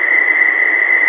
engine-turbine-2.wav